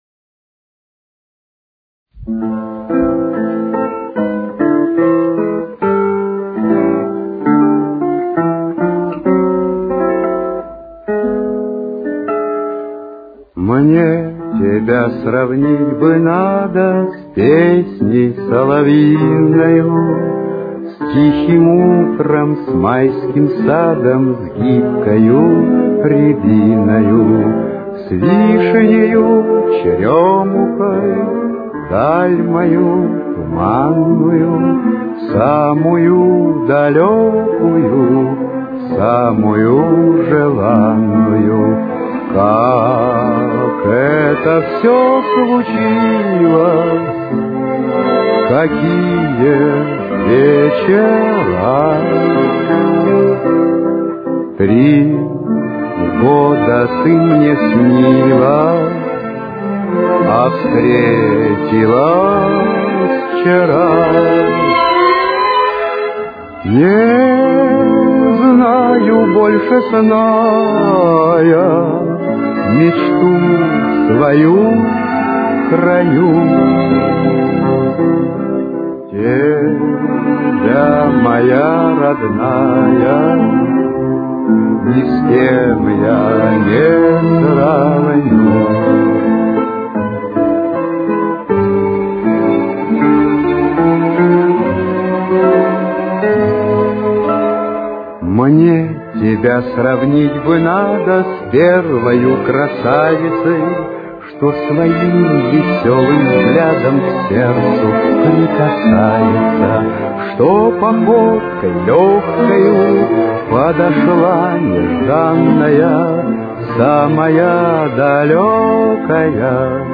Ля минор. Темп: 72.